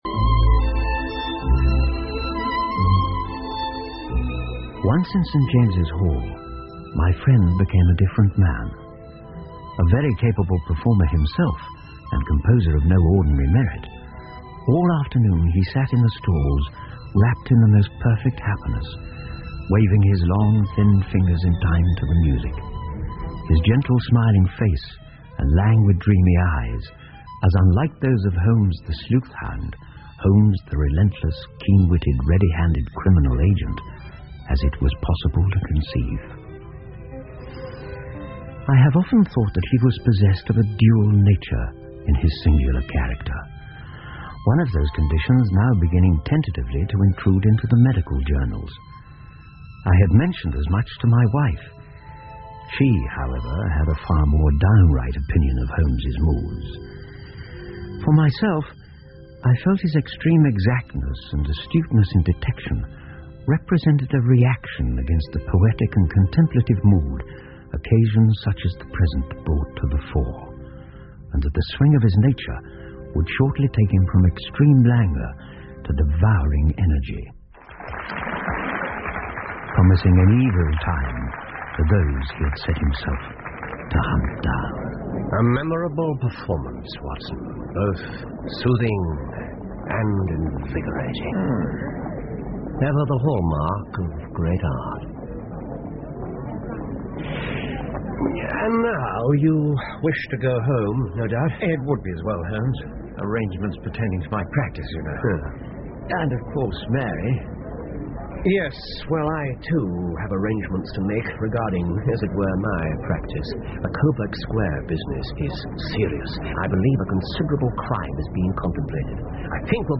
福尔摩斯广播剧 The Red Headed League 7 听力文件下载—在线英语听力室